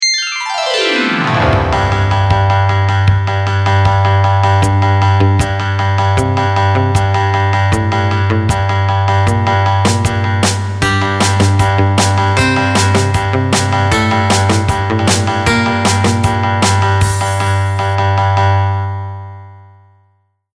| surf music |